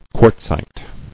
[quartz·ite]